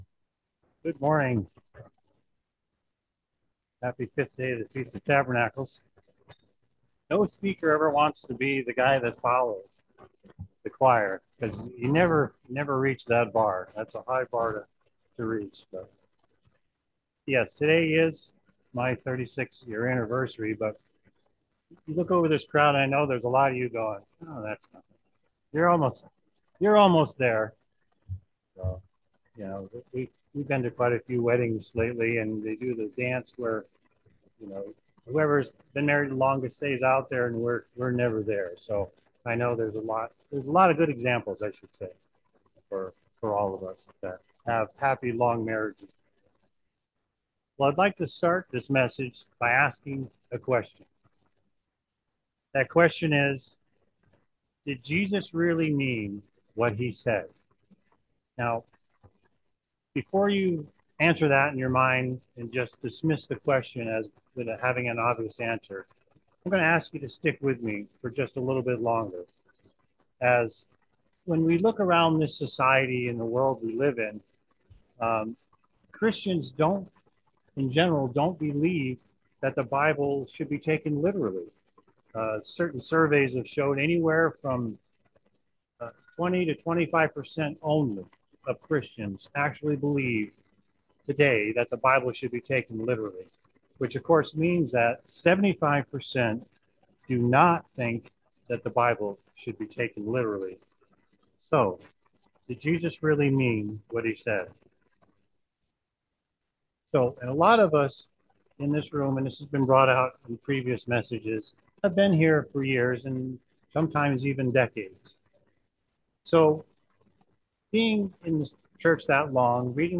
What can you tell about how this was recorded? This sermon was given at the Lake Geneva, Wisconsin 2023 Feast site.